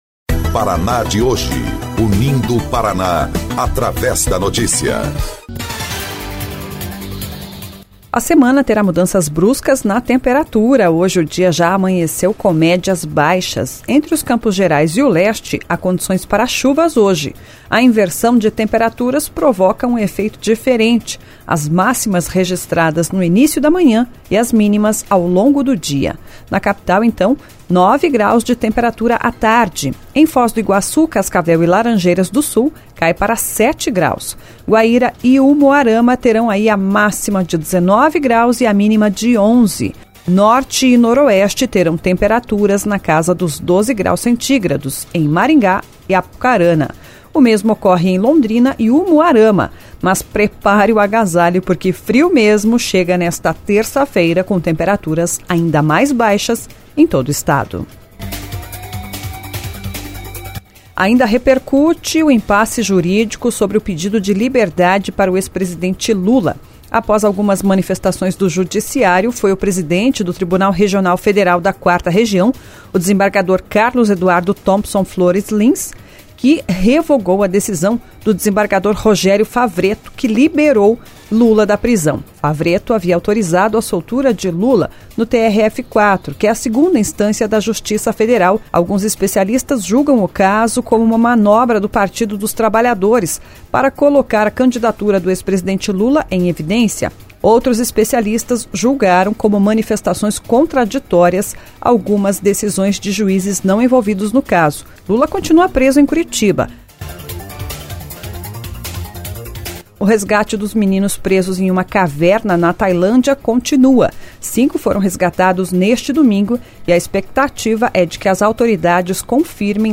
09.07 – GIRO NOTÍCIAS – MANHÃ